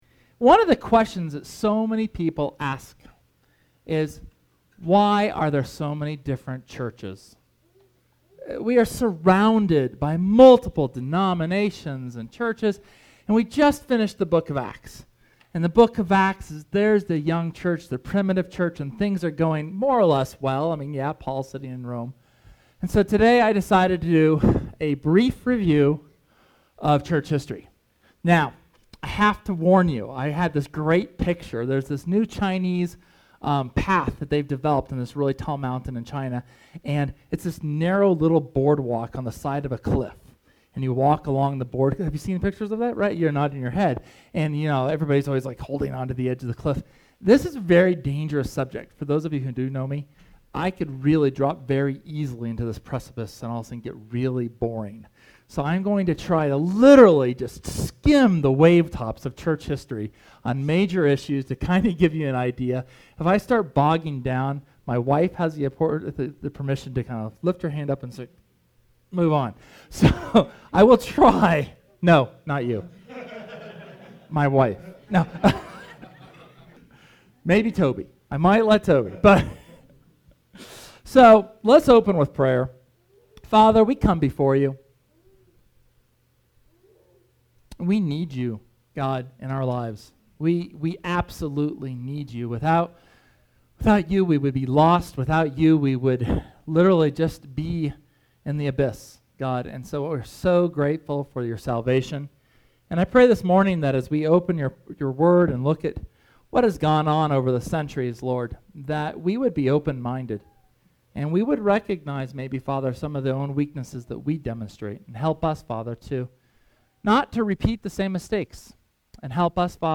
SERMON: What then?